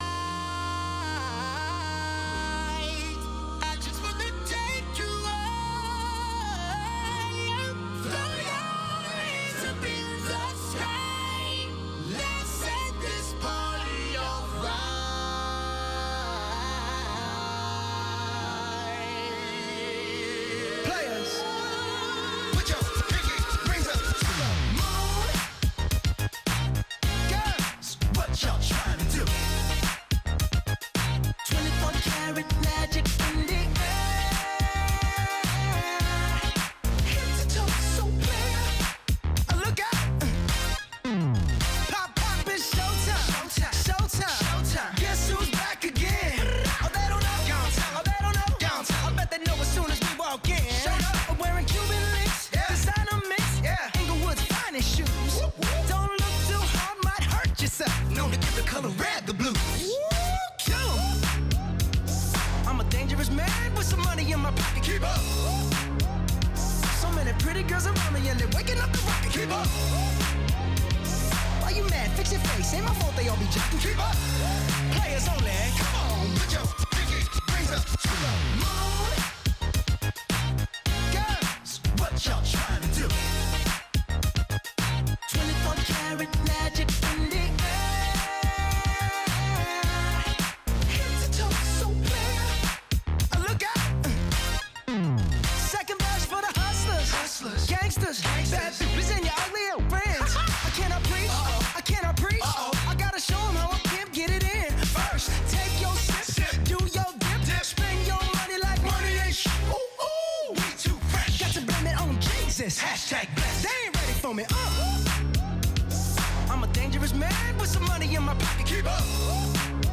Entrevista VOA - 12:30pm
La Voz de América entrevista, en cinco minutos, a expertos en diversos temas.